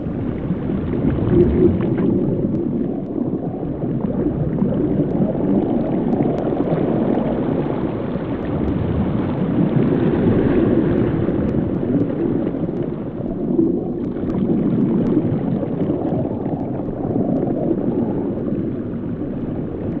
Âm thanh môi trường Dưới nước sâu
Thể loại: Tiếng thiên nhiên
Description: Âm thanh môi trường Dưới nước sâu là hiệu ứng tiếng chuyển động của nước, âm thanh của các bọt khí nổi lên để lấy oxy của các loài động vật, tiếng ù ù của nước ở khu vực sâu, tiếng bong bóng nước và tiếng sóng biển rì rào ngăn cách bởi mặt nước, âm thanh đáy đại dương mở ra nhiều câu chuyện bí ẩn, âm thanh gợi ra sự tò mò về những điều kì bí dưới đáy đại dương.
Am-thanh-moi-truong-duoi-nuoc-sau-www_tiengdong_com.mp3